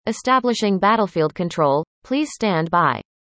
All the sounds were generated using text to speech technology.